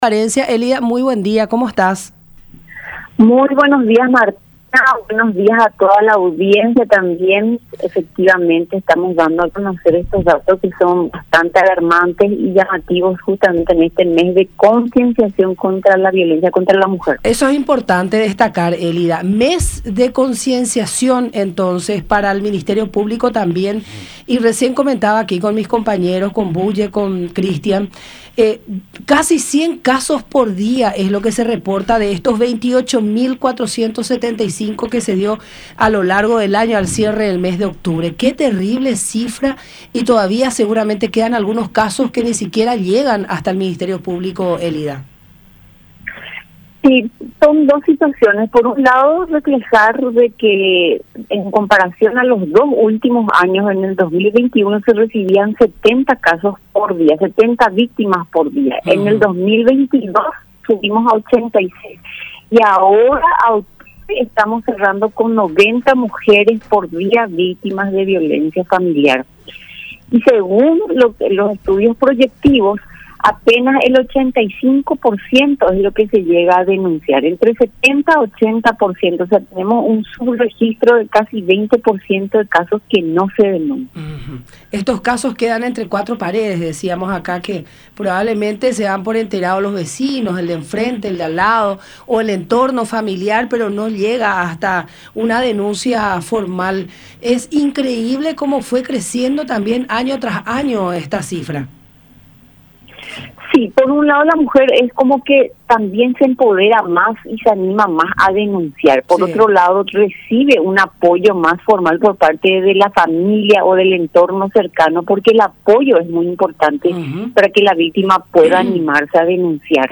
“En el 2021 se recibían 70 victimas por día, el 2022 subimos a 86 y ahora estamos cerrando con 90 casos por día y según un estudio proyectivo entre 70 a 80% de los casos son los que se denuncian”, agregó en el programa “La Mañana De Unión” por radio La Unión y Unión Tv.